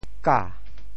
绞（絞） 部首拼音 部首 糹 总笔划 12 部外笔划 6 普通话 jiǎo 潮州发音 潮州 ga2 文 中文解释 绞〈动〉 (会意。